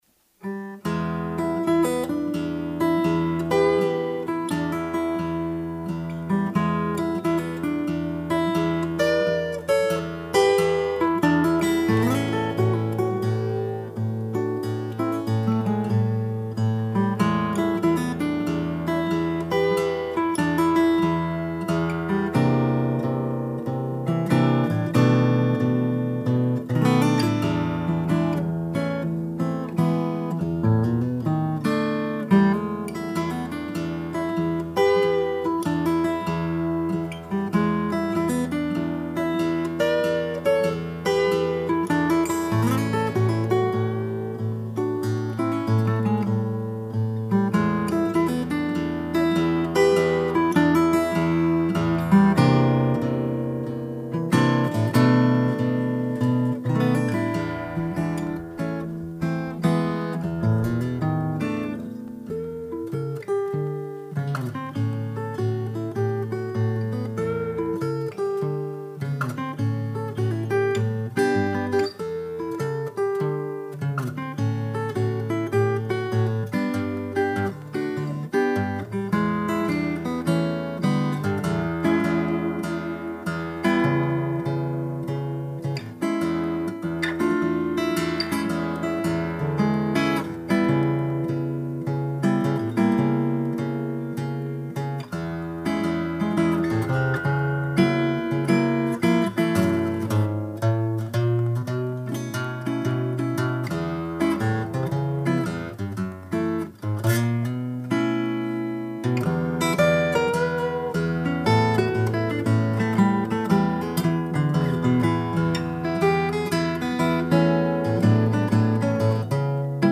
... stelle ich euch mal eine kleine Blues-Box vor, die just fertig geworden ist.
 Bauform: Double-O 14-bündig / Dreadnought Zargentiefe
Kleiner Blues